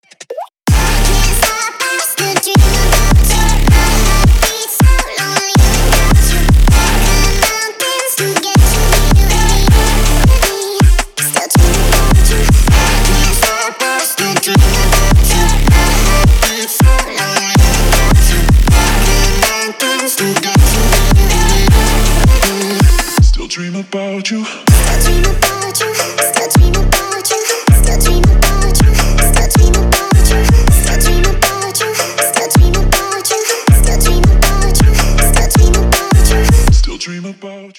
Trap рингтоны
Trap рингтон на мобильник